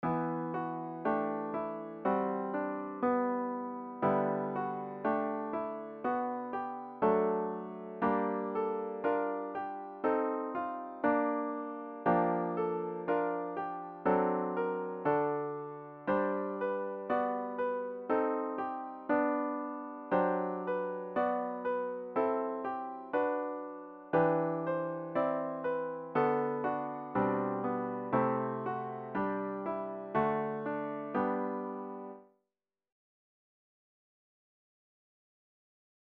About the Hymn
The hymn should be performed at a fervent♩= ca. 60.